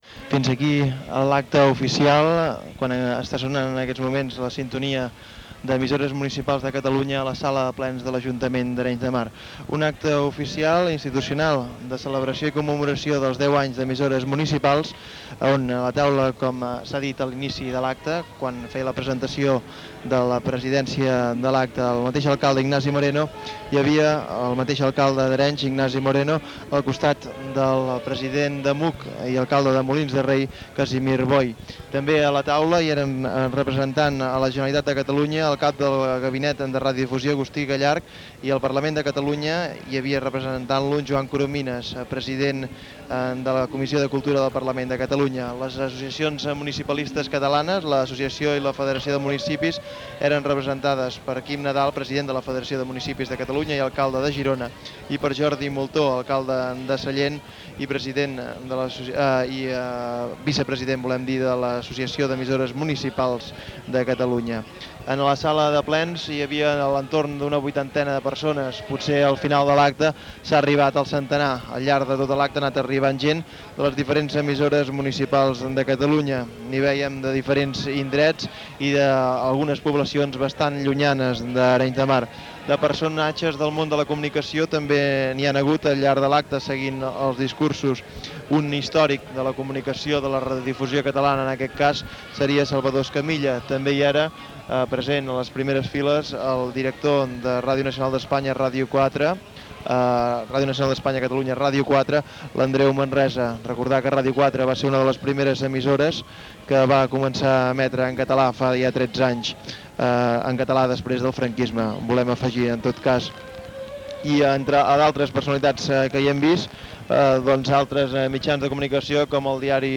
Transmissió de l'acte oficial de celebració dels 10 anys d'EMUC a Arenys de Mar. Personalitats assistents i resum.
Informatiu